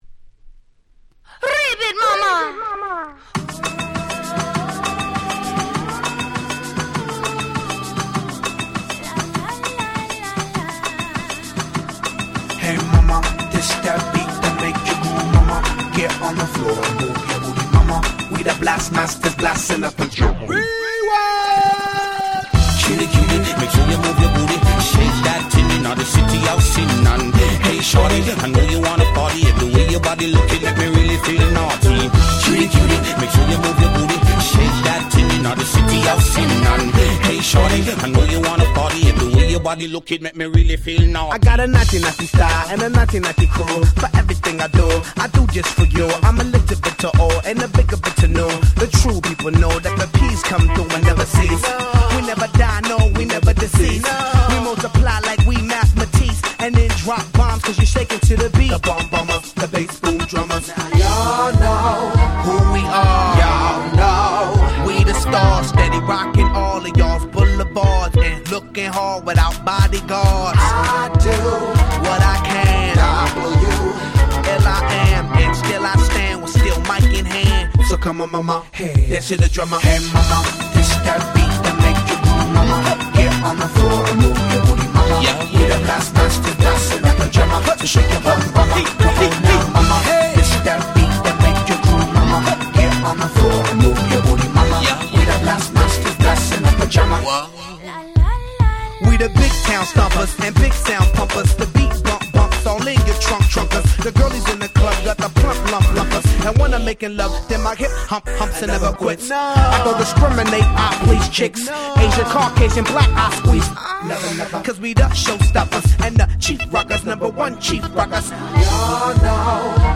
03' Super Hit Hip Hop / R&B !!
キャッチー系